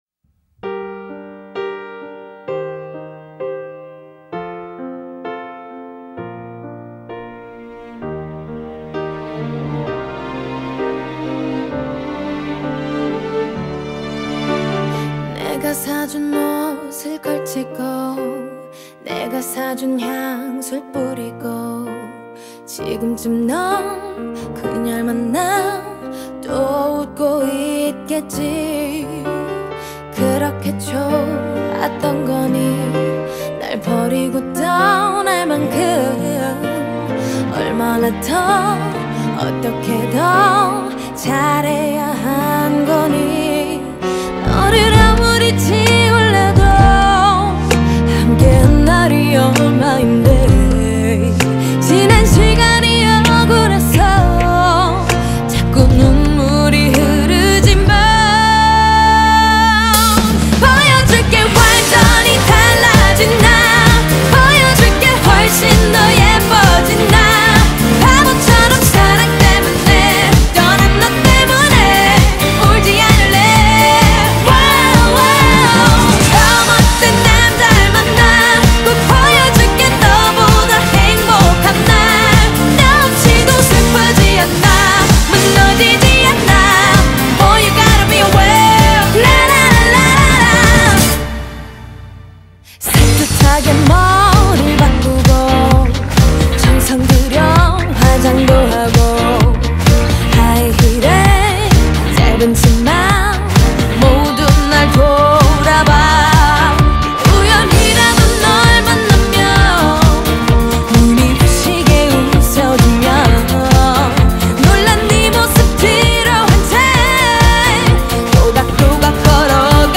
Эмоциональный вокал
R&B и поп